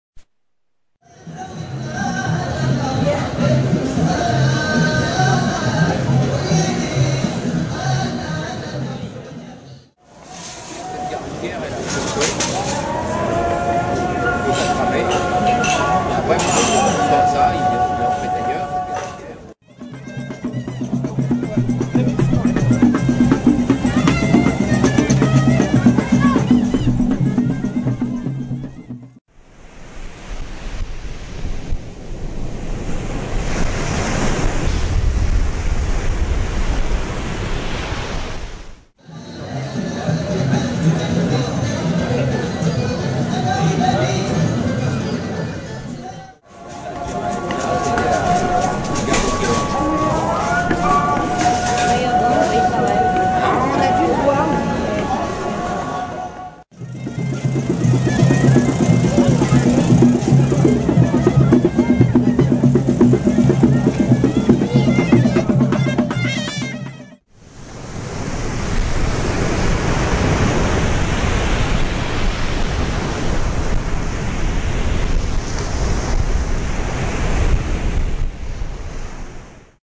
Wirksworth Festival, Moot Hall, Wirksworth, UK
Wirksworth Festival, Moot Hall, Wirksworth, UK A variety of sounds collected in Morocco and housed inside little Moroccan tagines made of plaster. The sounds are transmitted via little speakers wired up inside the tagines.